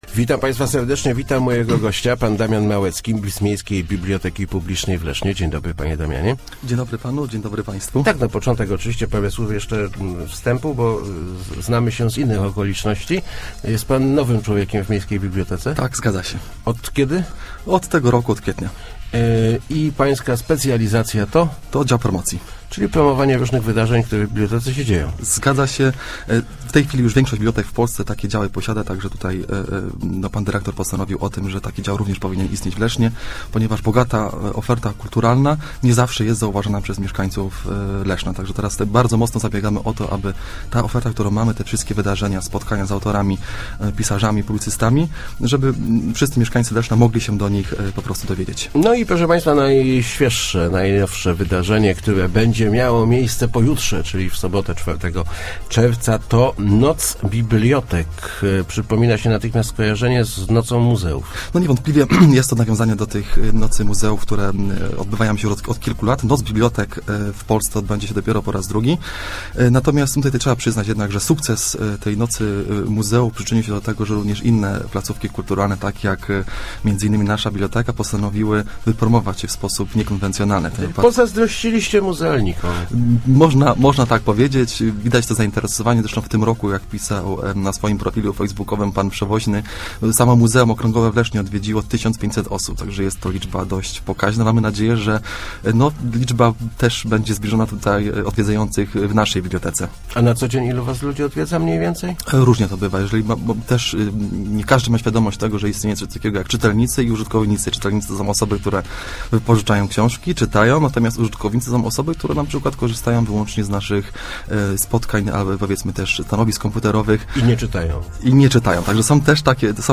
Fajny wywiad na pewno przyjdę!